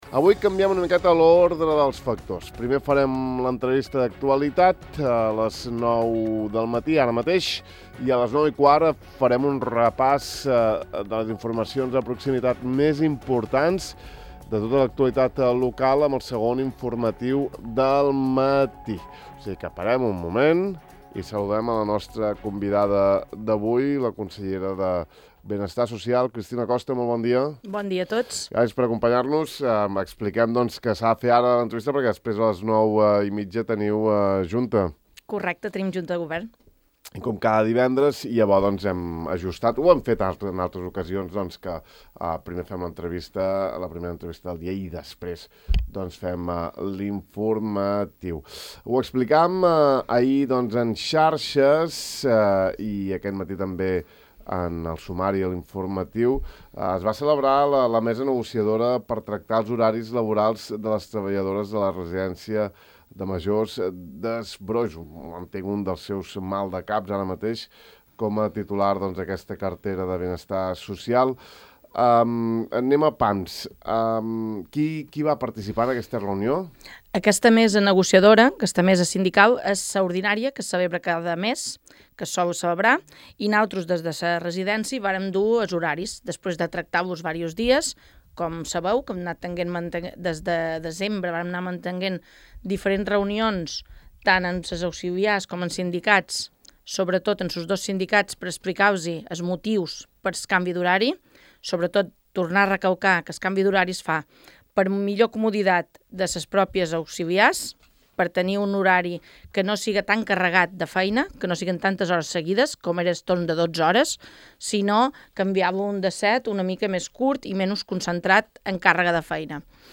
La consellera insular de Benestar Social, Cristina Costa, explica a Ràdio Illa que la setmana vinent es portarà a terme la reunió entre la direcció i treballadores de la Residència per sotmetre a votació a mà alçada les diverses opcions d’horaris laborals, un assumpte que fins ara ha causat tibantors entre les parts implicades.